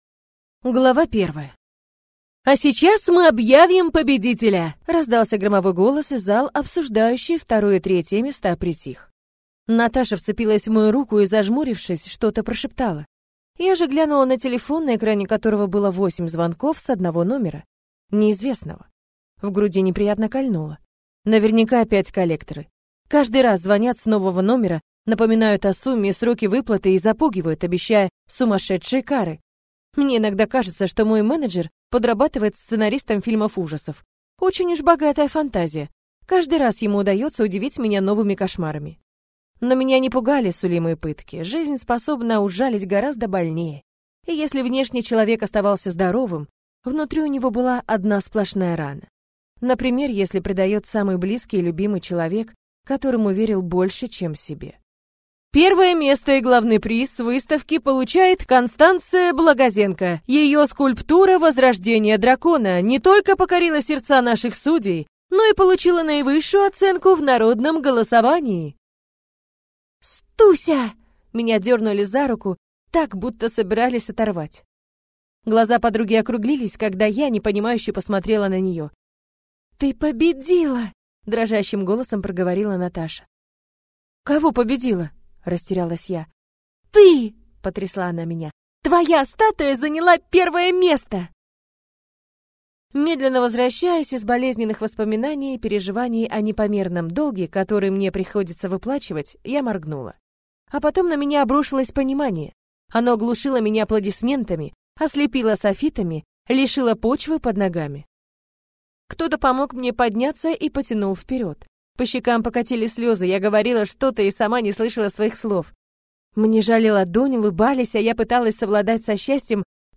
Аудиокнига Моя мачеха – иномирянка | Библиотека аудиокниг